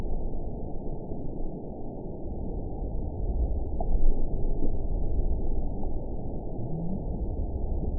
event 917768 date 04/15/23 time 17:16:20 GMT (2 years, 7 months ago) score 8.46 location TSS-AB05 detected by nrw target species NRW annotations +NRW Spectrogram: Frequency (kHz) vs. Time (s) audio not available .wav